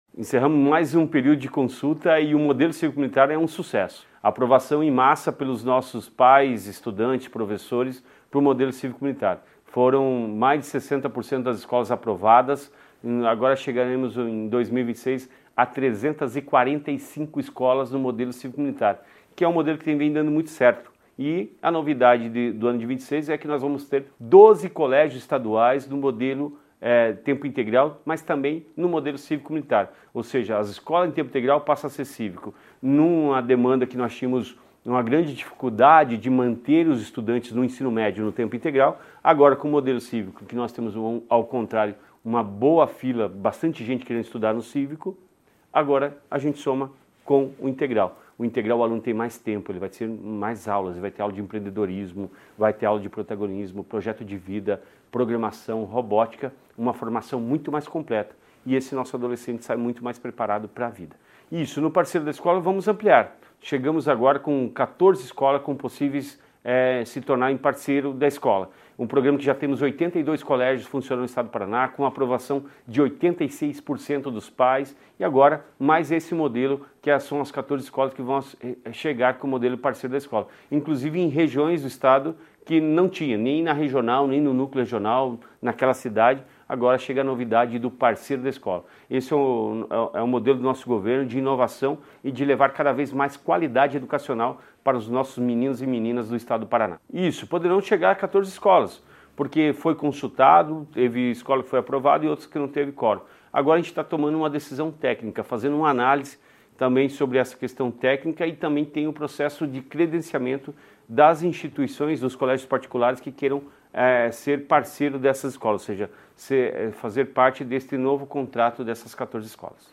Sonora do secretário da Educação, Roni Miranda, sobre o resultado da consulta pública sobre colégios cívico-militares